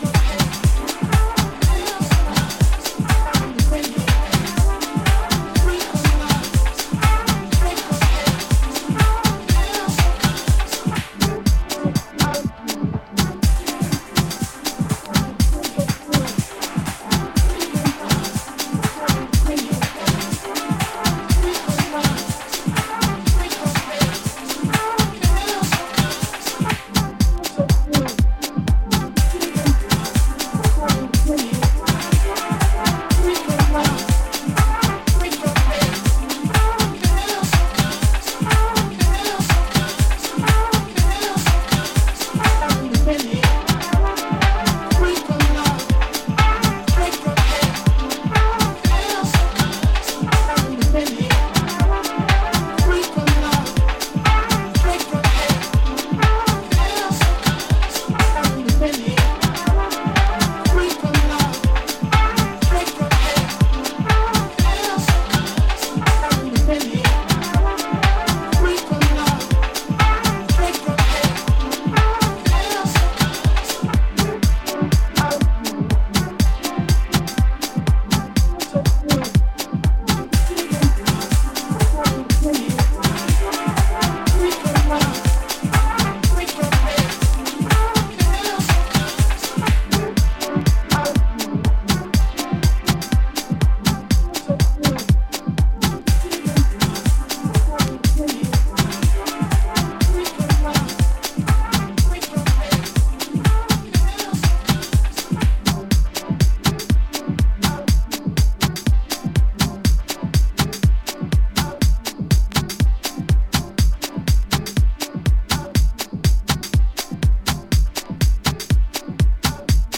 いずれもジャジーでオーセンティックな魅力を秘めた